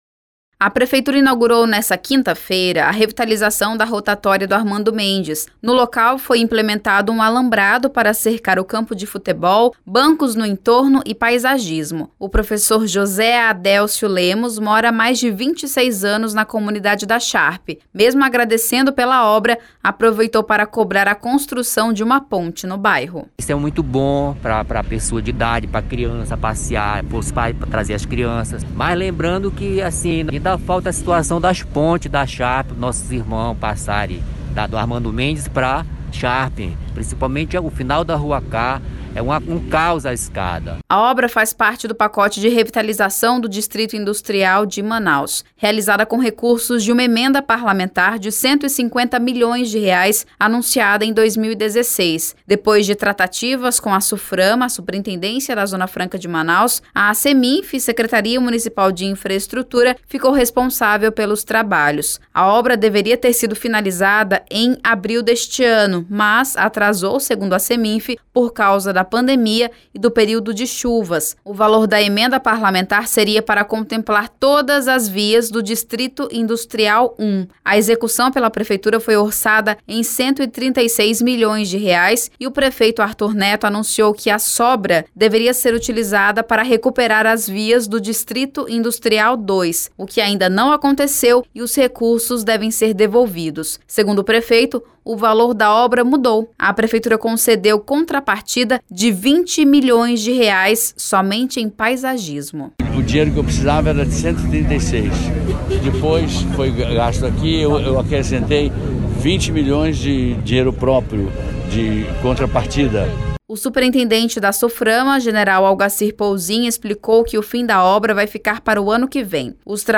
Segundo Arthur Neto, a prefeitura concedeu contrapartida de 20 milhões de reais, além do previsto, para custear o paisagismo dos locais onde foi realizada a obra. A conclusão dos trabalhos vai ficar para o próximo ano. Ouça a reportagem: